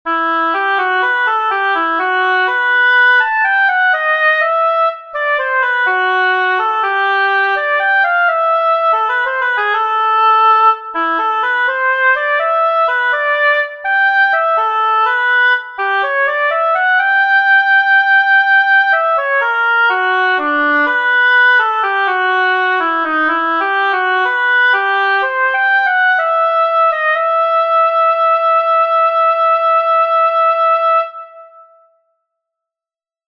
Música antiga
A1-dictat-melodic-antiga-audio-24-06.mp3